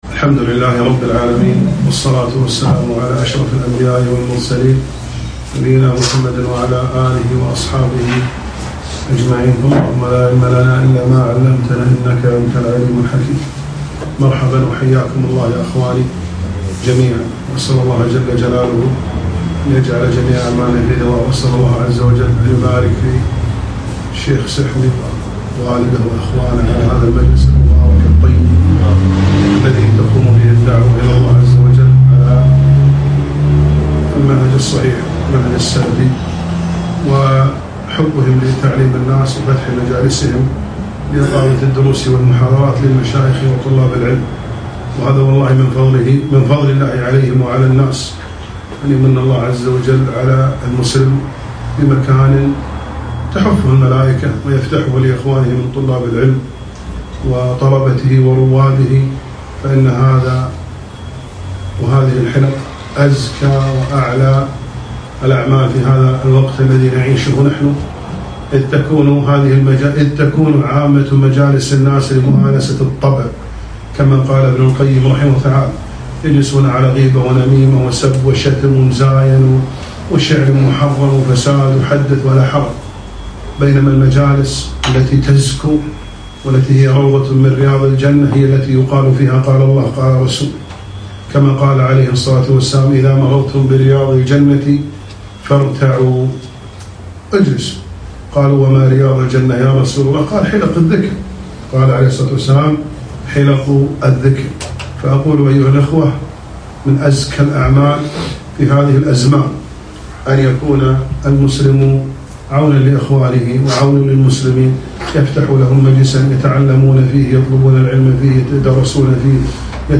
محاضرة - محرمات استهان بها كثير من الناس